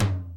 Southside Percussion (8).wav